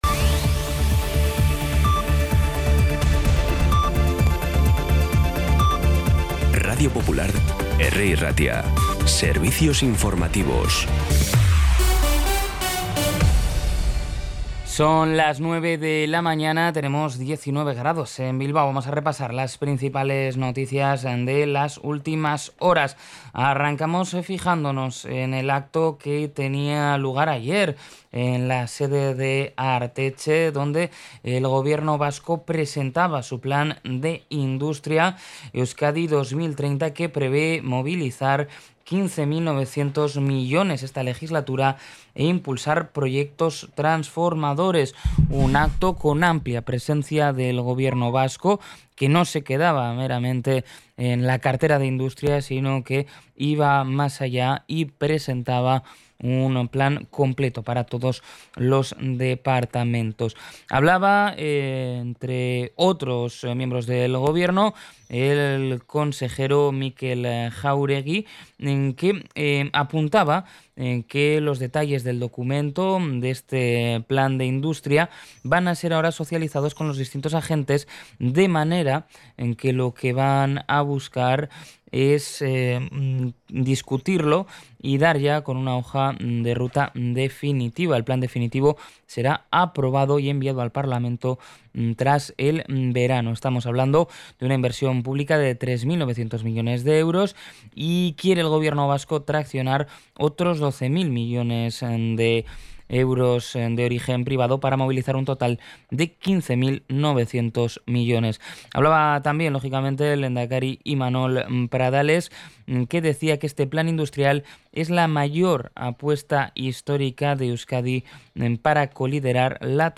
Las noticias de Bilbao y Bizkaia del 12 de junio a las 9
Los titulares actualizados con las voces del día.